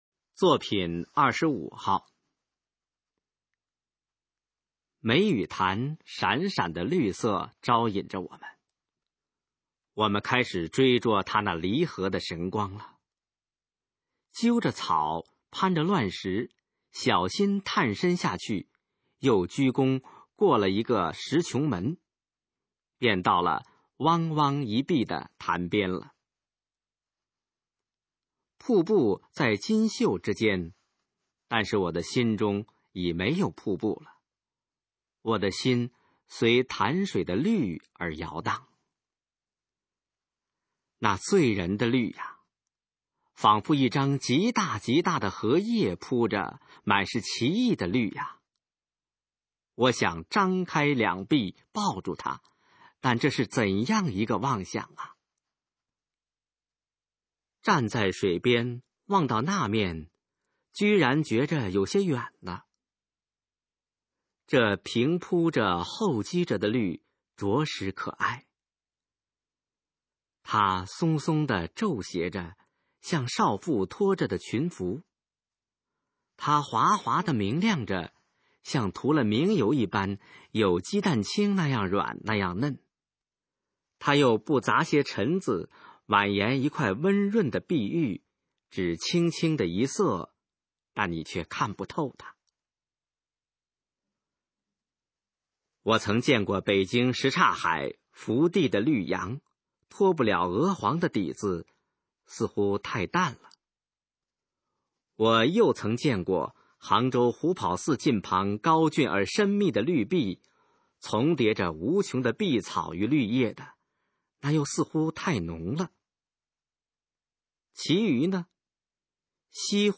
首页 视听 学说普通话 作品朗读（新大纲）
《绿》示范朗读_水平测试（等级考试）用60篇朗读作品范读